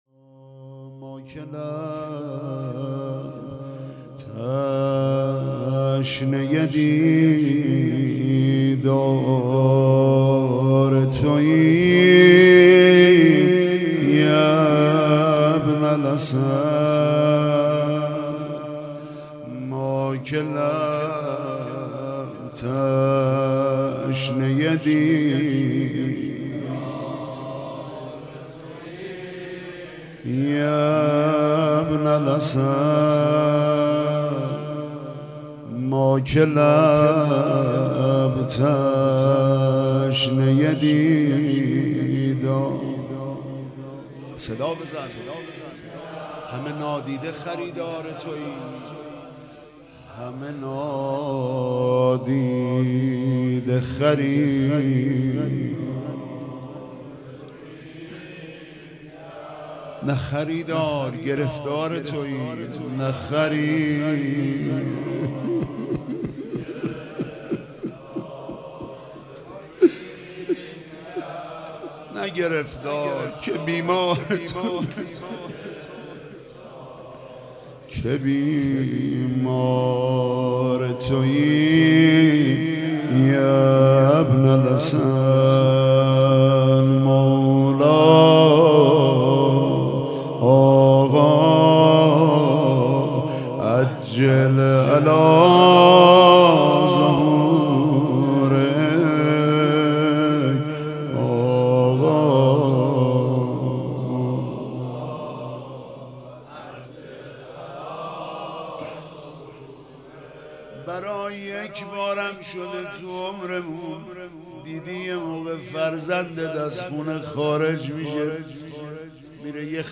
هیئت رایة الرضا علیه السلام | روضه هفتگی